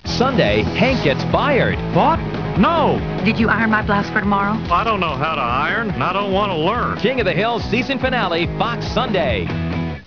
MIKE JUDGE as Hank Hill
KATHY NAJIMY as Peggy Hill
koh22310.mov (818k, Quicktime)   Audio Promo